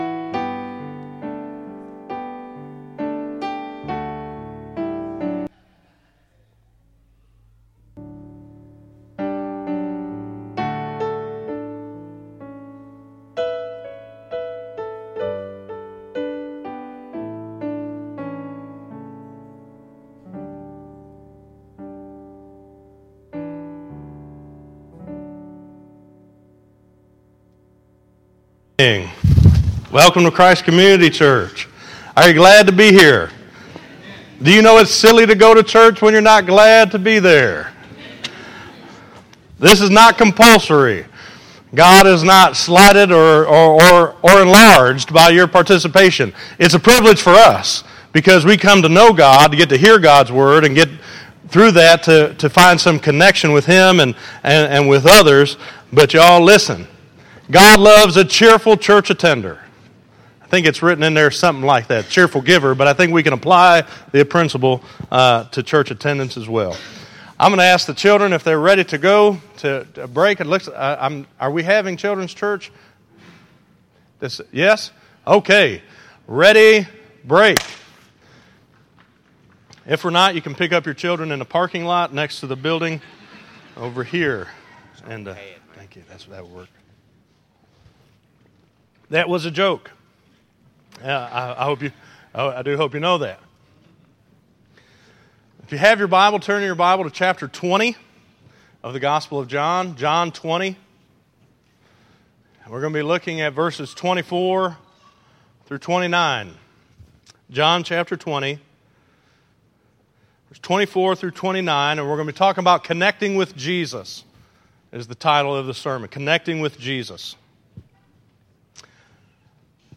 Listen to Connected With Jesus - 05_18_2014_Sermoon.mp3